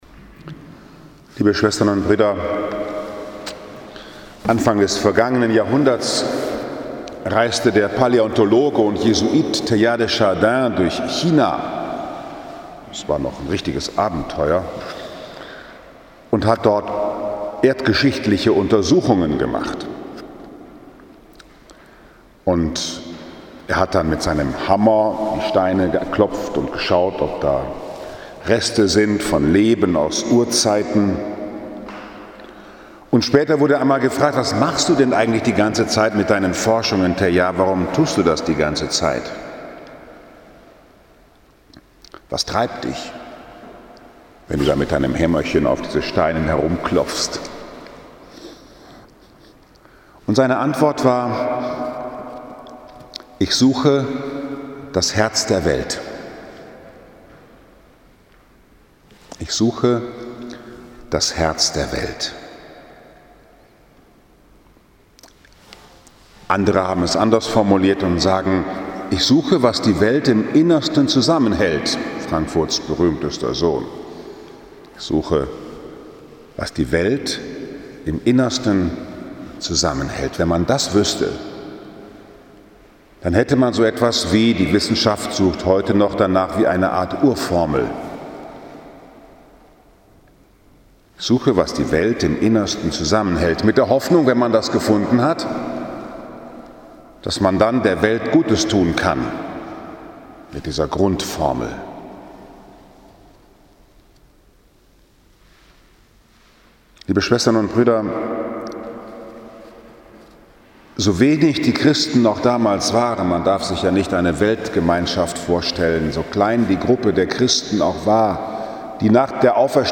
3. Januar 2021, 8 Uhr, Liebfrauenkirchen Frankfurt am Main, 2. Sonntag nach Weihnachten B